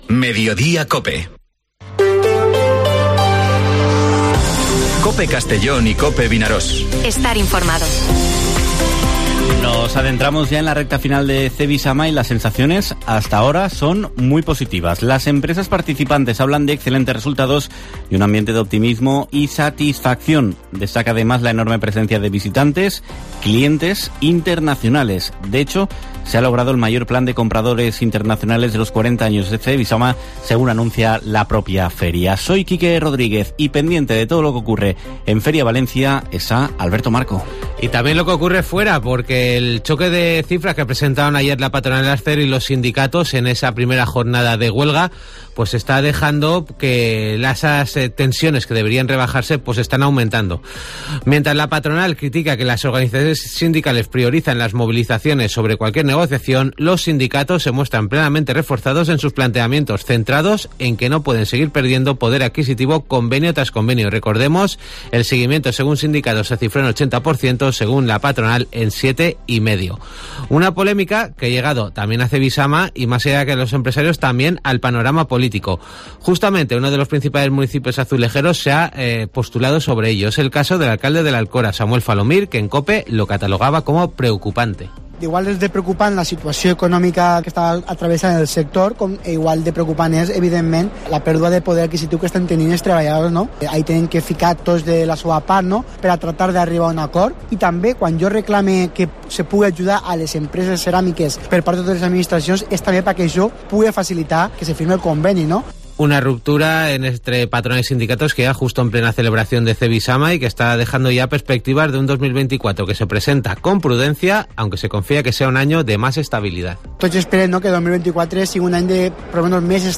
Noticias del jueves en Mediodía COPE: Buenas sensaciones en Cevisama; Proyectos de Onda y l´Alcora; 4 millones para mejoras en la CV-10; Benicàssim programa actividades para primavera; Junta de seguridad Fallas de Benicarló; Previa de Magdalena; Se reforzará el viento; Deportes...